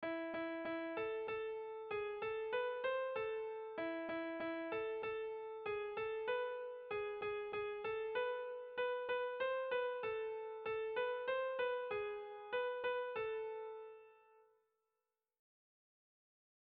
Sehaskakoa
AB